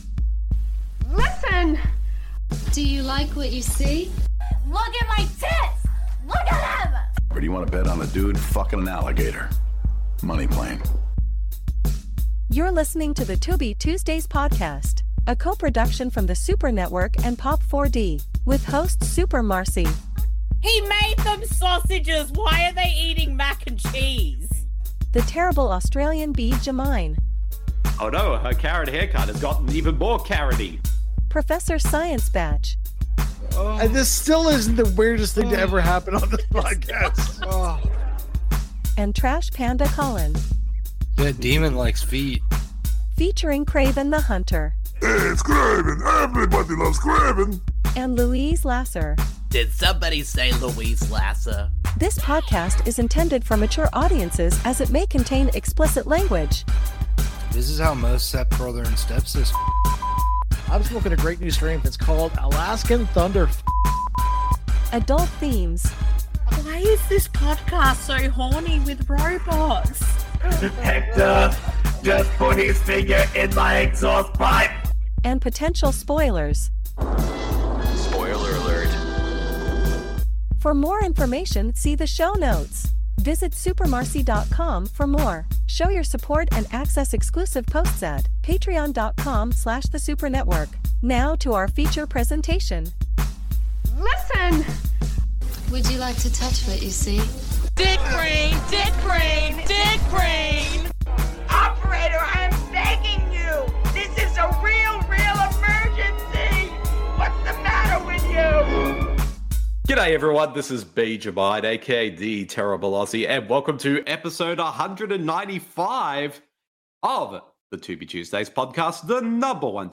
This podcast series is focused on discovering and doing commentaries/watch a longs for films found on the free streaming service Tubi, at TubiTV
Welcome back to The Tubi Tuesdays Podcast, the number one Tubi related podcast that’s hosted by two Australians, one Canadian and one American!